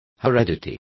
Complete with pronunciation of the translation of heredities.